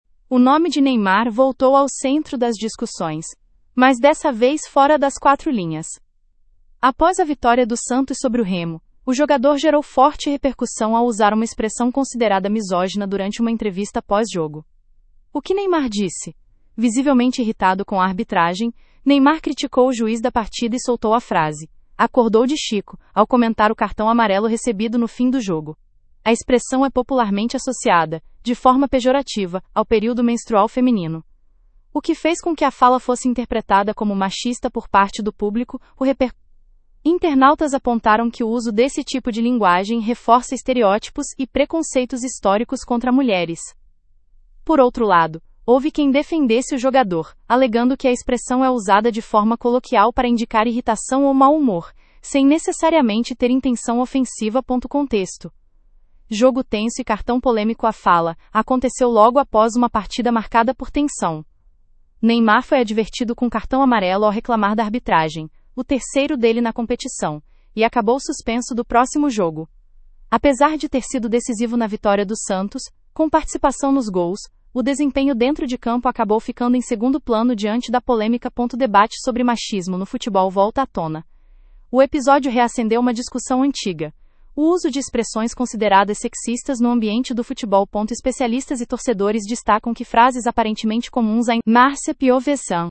Após a vitória do Santos sobre o Remo, o jogador gerou forte repercussão ao usar uma expressão considerada misógina durante uma entrevista pós-jogo.
Visivelmente irritado com a arbitragem, Neymar criticou o juiz da partida e soltou a frase: “acordou de chico”, ao comentar o cartão amarelo recebido no fim do jogo.